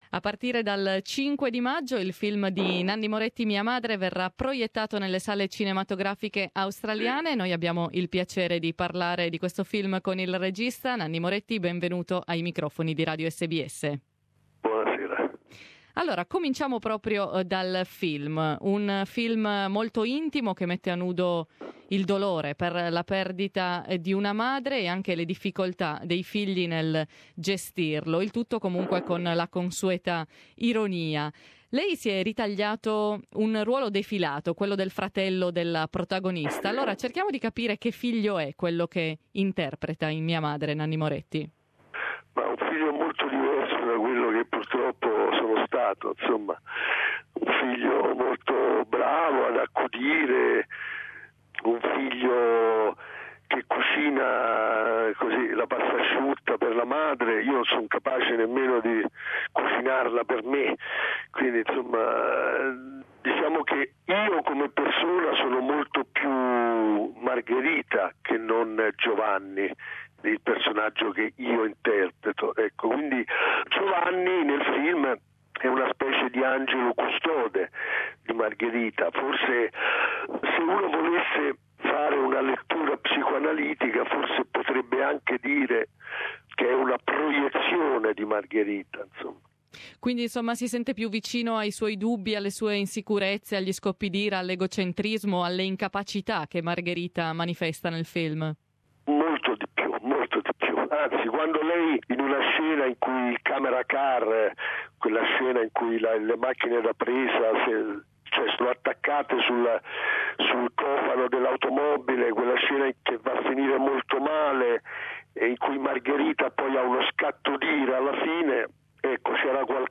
Interview with Italian director Nanni Moretti on his latest movie, the semi-autobiographical and award-winning "Mia Madre" (My Mother), which will be released in Australia on May 5th.